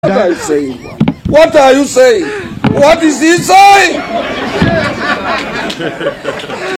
what is he saying Meme Sound Effect
This sound is perfect for adding humor, surprise, or dramatic timing to your content.